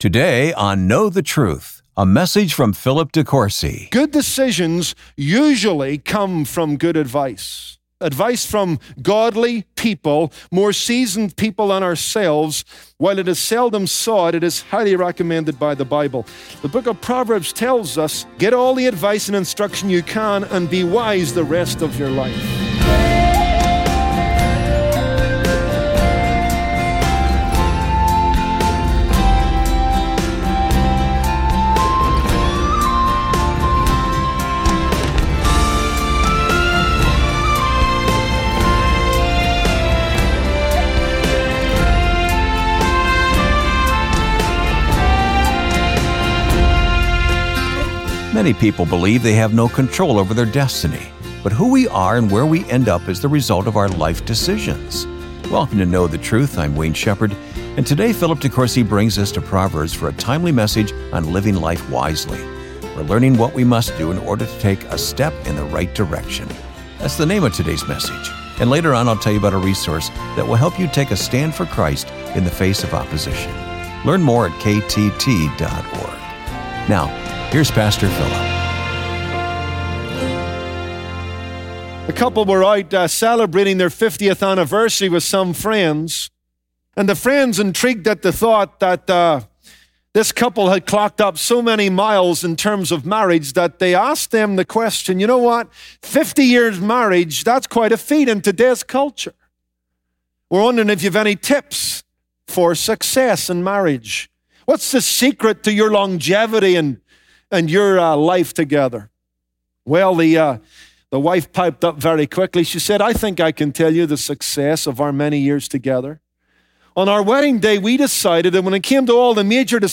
Many people believe they that have no control over their destiny. But who we are and where we end up, is the result of our life decisions! On this Thursday broadcast